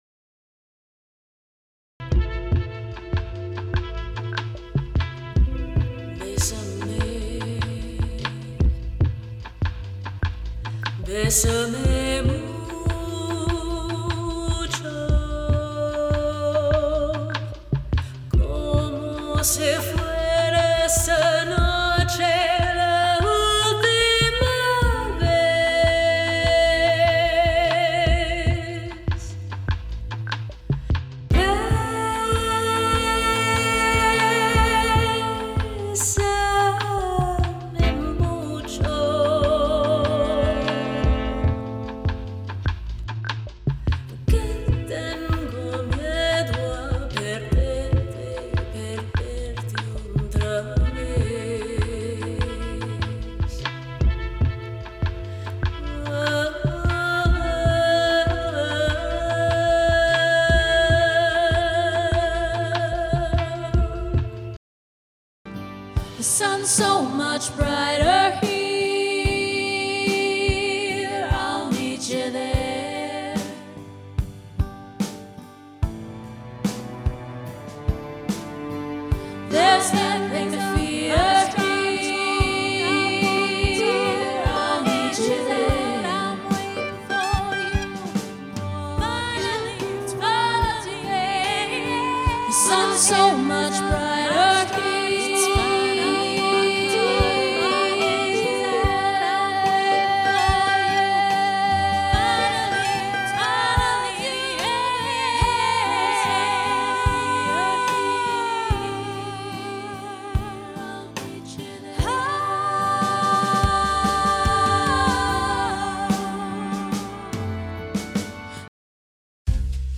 1. Singing Reel
I have a professional sound treated recording studio with industry standard microphones, equipment, and recording / audio editing software.
Singing styles include jazz, rock, pop, blues, latin, folk, and improvisation.